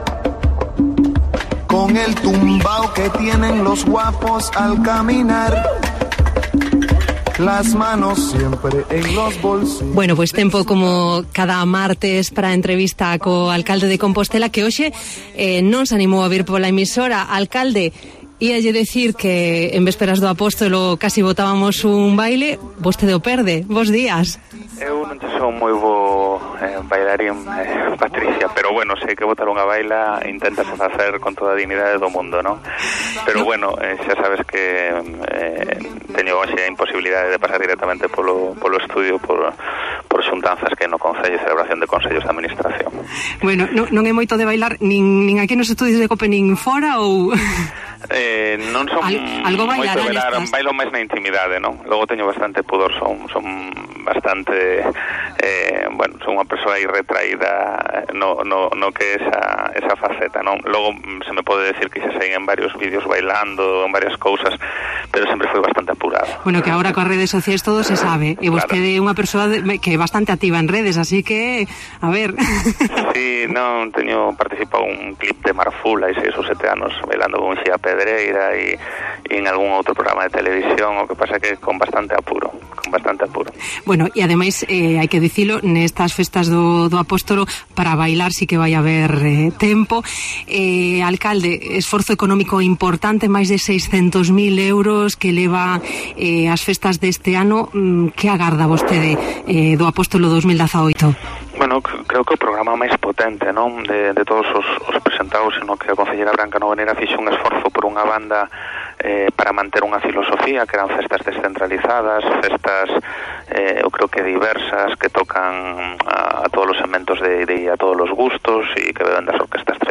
Entrevista política al alcalde de Santiago en Cope+Santiago: Martiño Noriega insiste en que la propuesta de "intermediación" de Compostela Aberta...